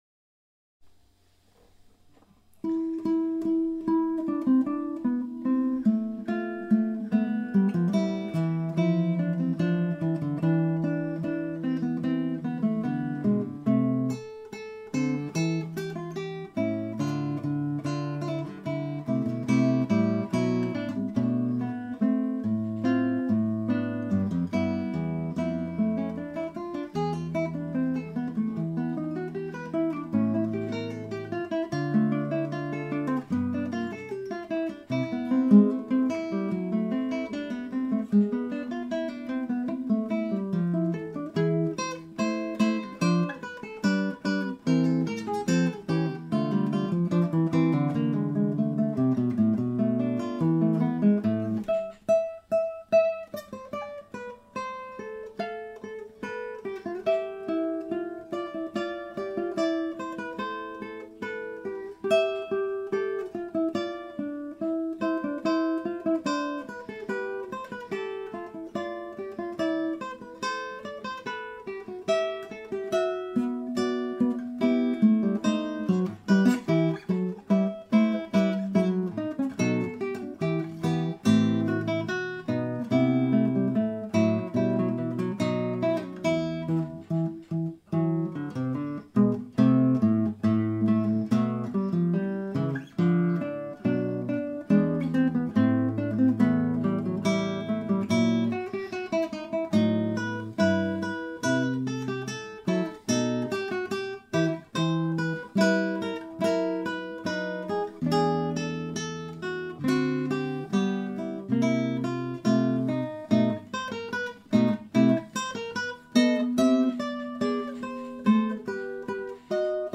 Acoustic Guitar Recording 101
It is pure mono, and the ambient noise intrudes - not just the room, but breaths, foot shuffling, etc. BUT, terrific playing.
Unasked, so apologies, but I did a slight de-noise, pulled down that low-mid hump a bit, added a bit of reverb (a matter of taste, and no time spent finding the best IR) and what I think is undetectable compression/limiting here.